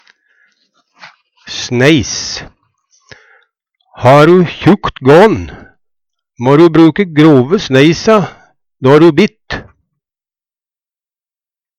sneis - Numedalsmål (en-US)